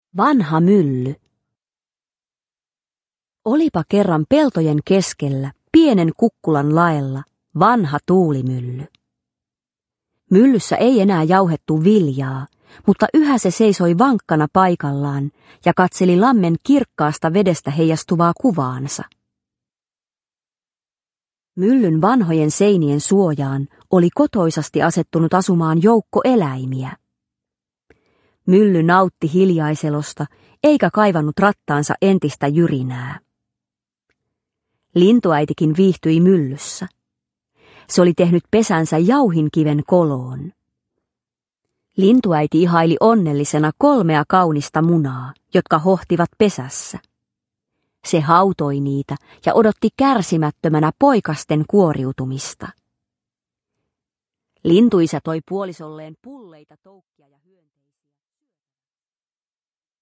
Vanha mylly (ljudbok) av Disney